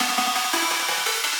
SaS_Arp04_170-C.wav